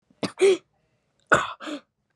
Bro Can't Stop Breathing Bouton sonore